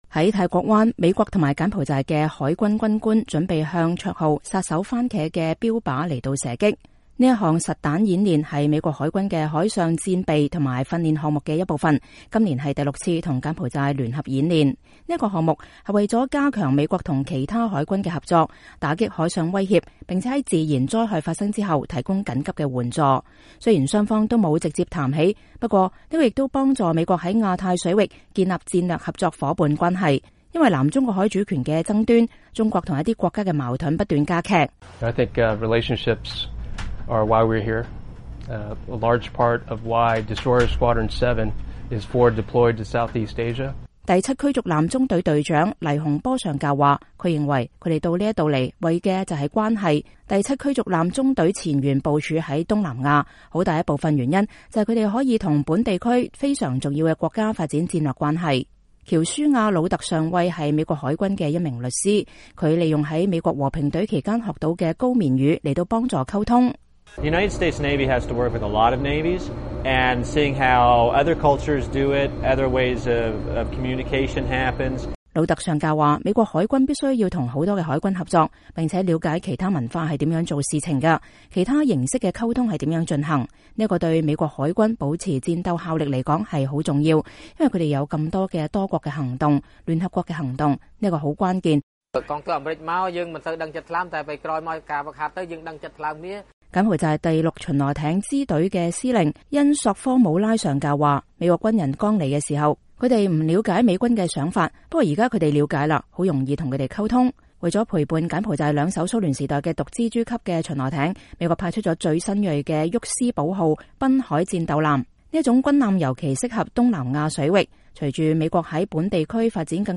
2015-11-24 美國之音視頻新聞: 美柬海軍在泰國灣聯合演習